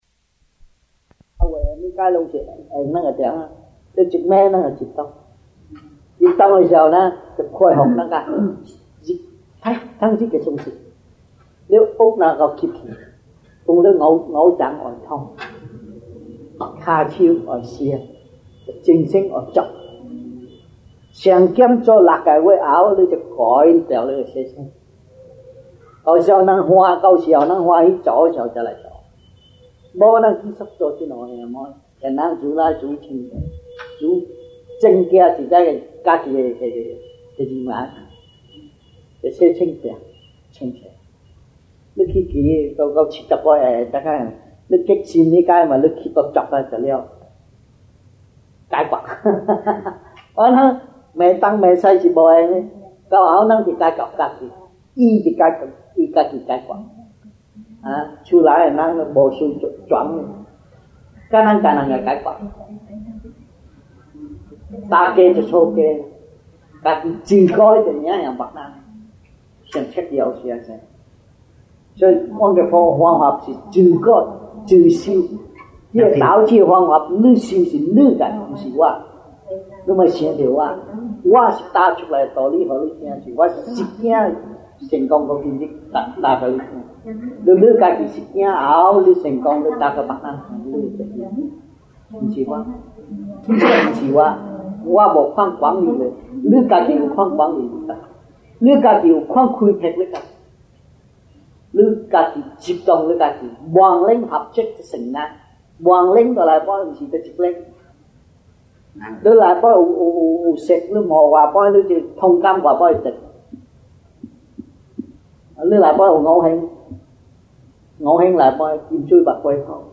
Lectures-Chinese-1981 (中文講座)